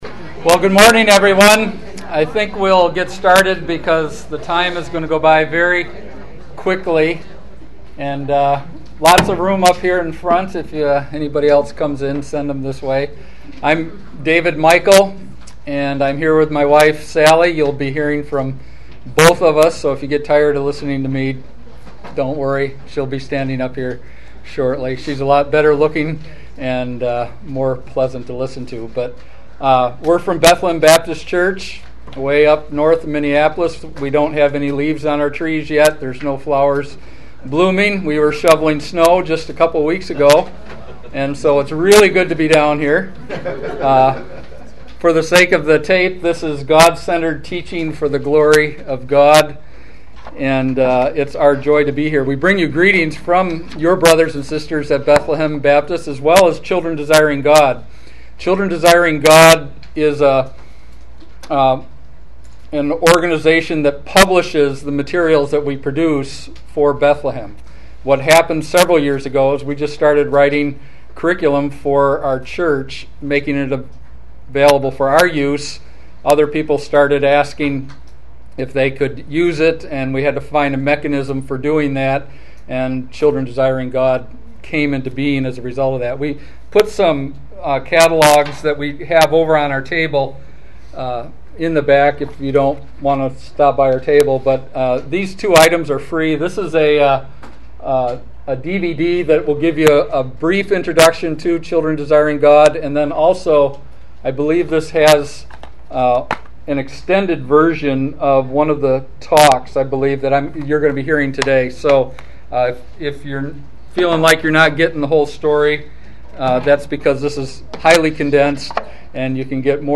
Conferences